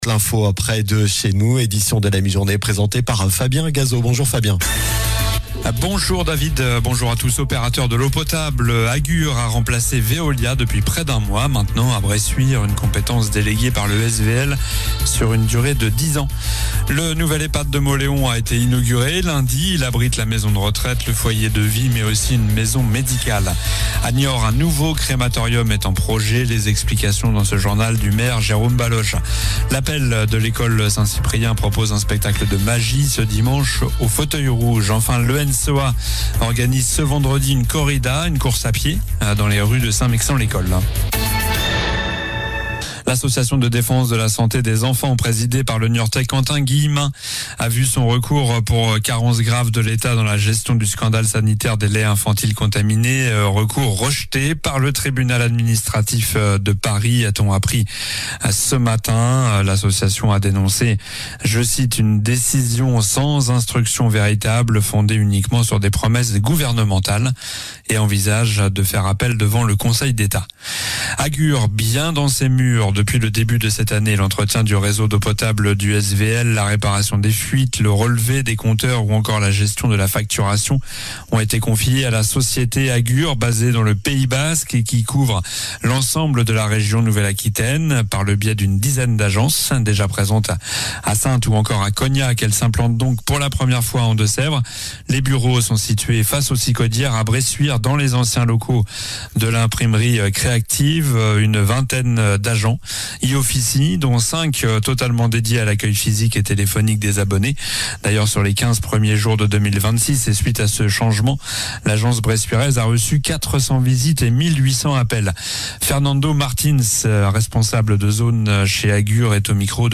Journal du mercredi 28 janvier (midi)